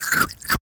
comedy_bite_creature_eating_04.wav